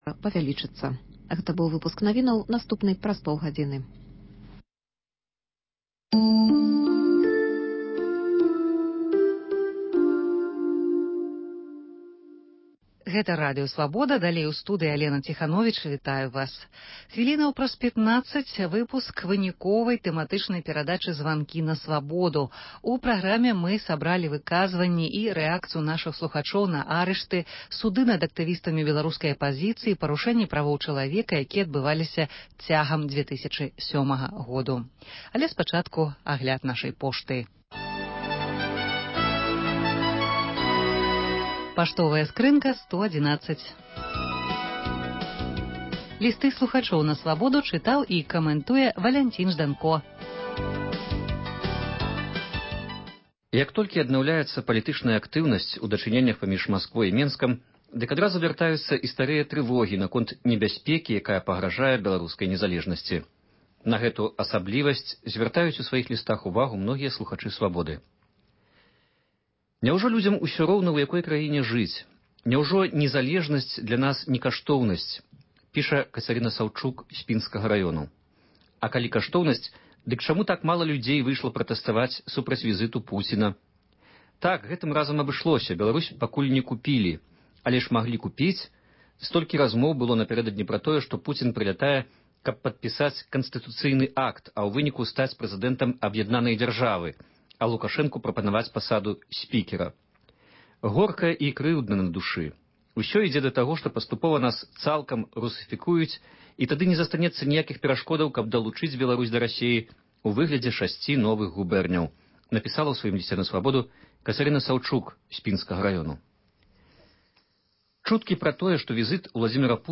Слухачы аб рэпрэсіях у Беларусі